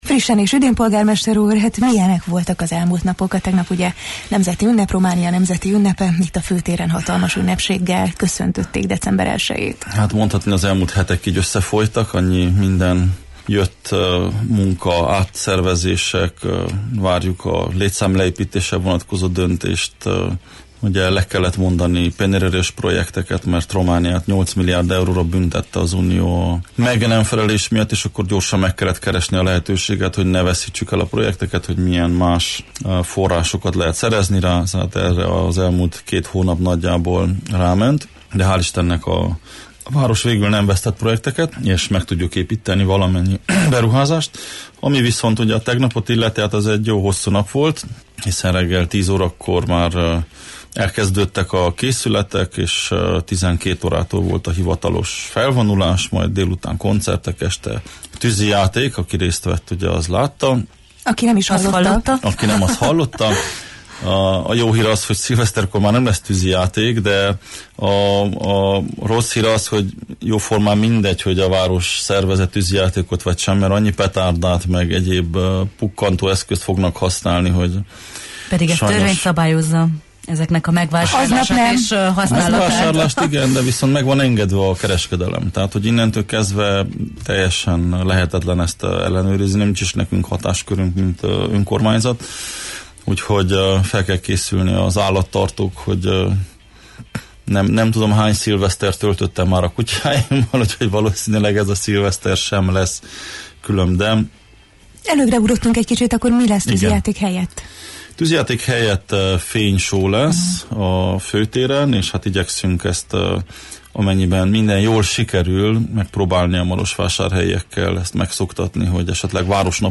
A Jó reggelt, Erdély! műsor vendége volt Soós Zoltán, Marosvásárhely polgármestere, aki az idei év kihívásairól, a legfontosabb beruházásokról, a jövőbeni tervekről és a közelgő karácsonyi programokról beszélt.